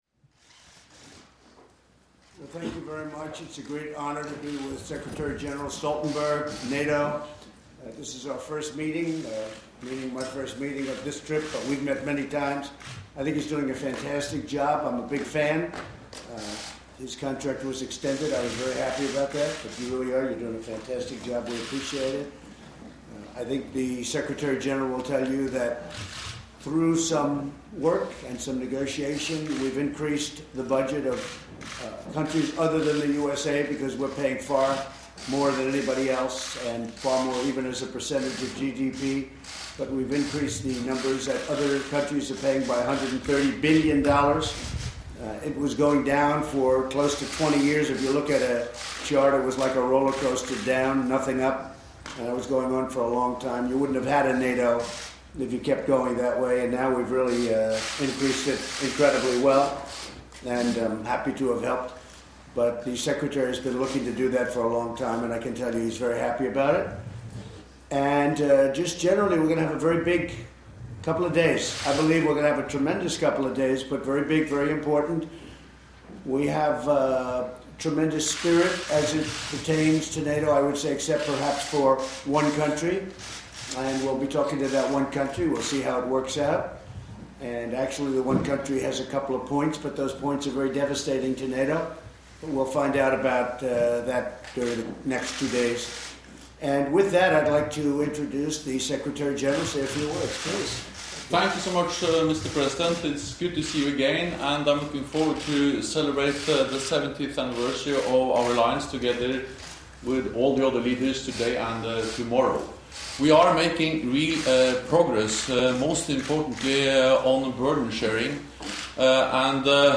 Statement
by NATO Secretary General Jens Stoltenberg with US President Donald Trump